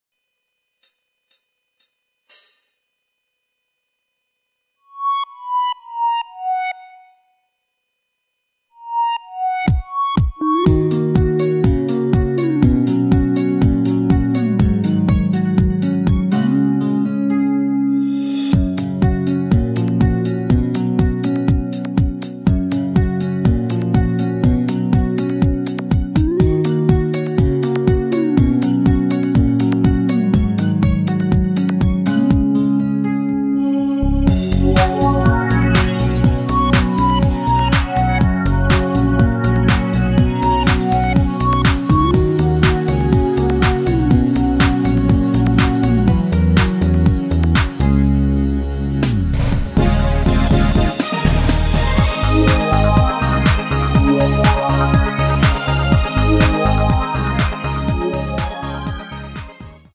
-4)내린 MR에 피아노 파트가 없는 MR입니다.